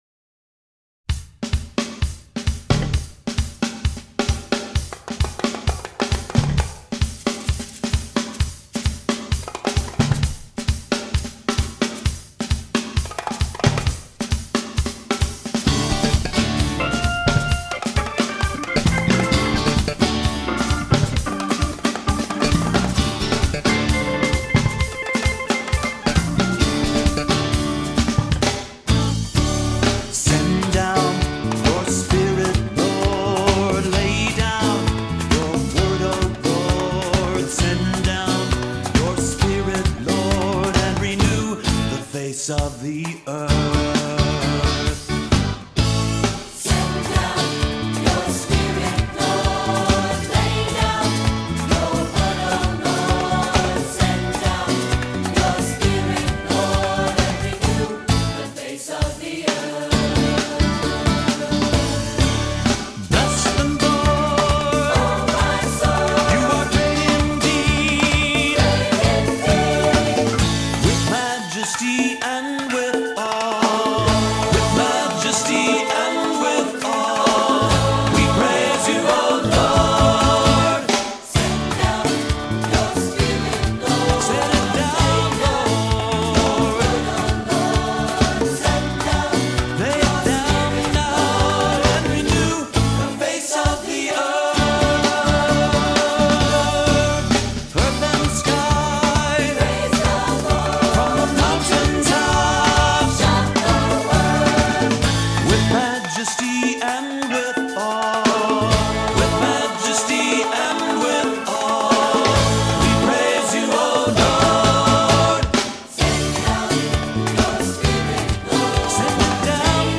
Voicing: SATB; Solo; Assembly